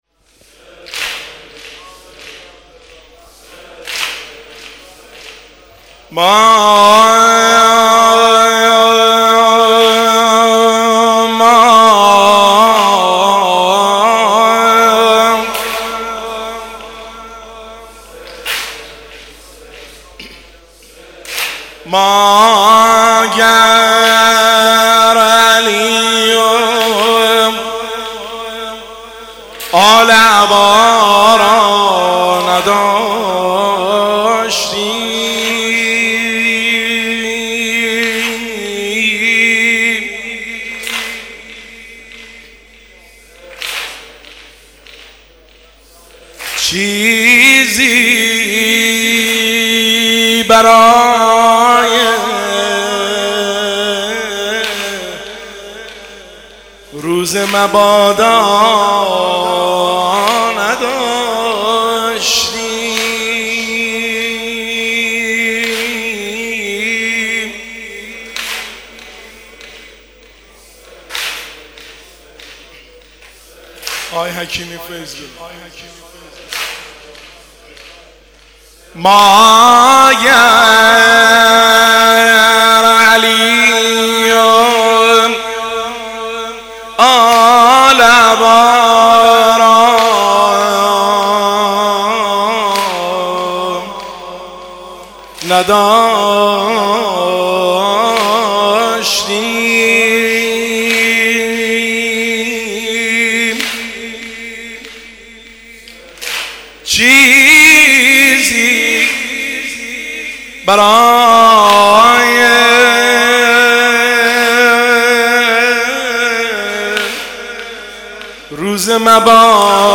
هیئت هفتگی 24 اردیبهشت 1404